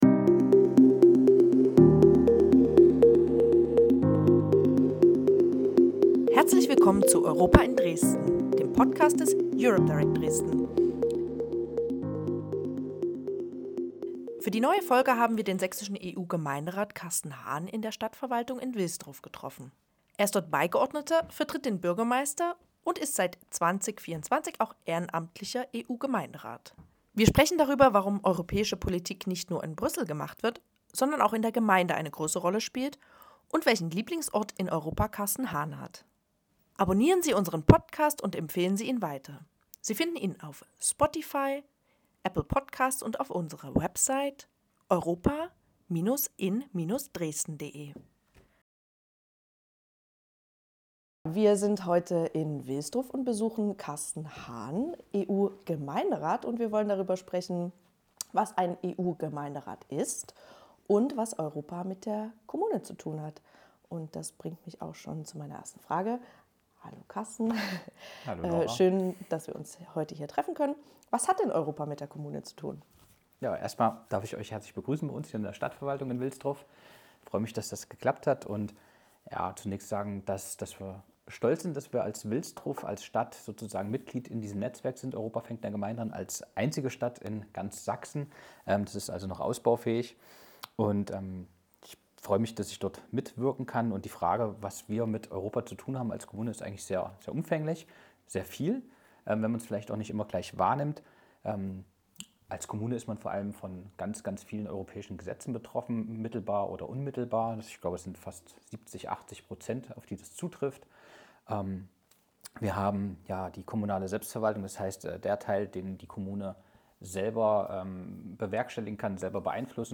Für die neue „Europa in Dresden“-Folge haben wir den sächsischen EU-Gemeinderat Carsten Hahn in der Stadtverwaltung in Wilsdruff getroffen.